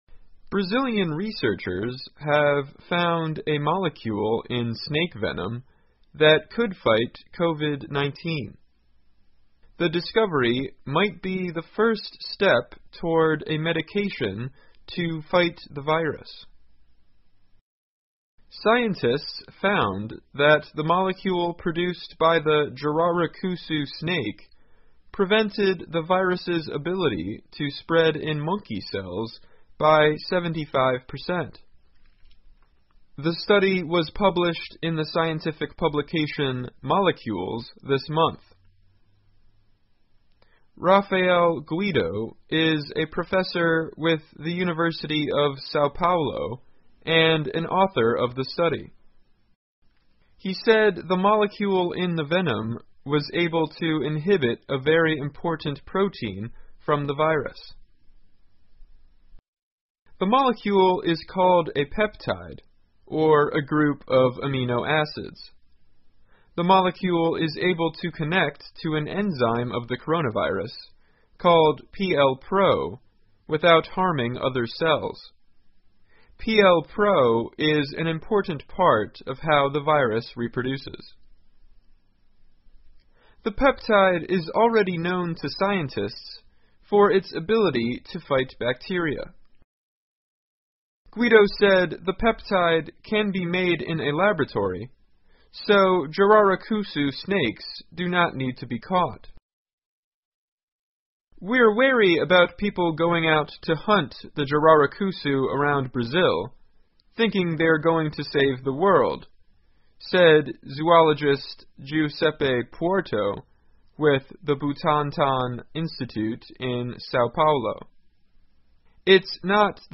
VOA慢速英语2021 研究称巴西蛇毒成分可能有助于对抗新冠肺炎 听力文件下载—在线英语听力室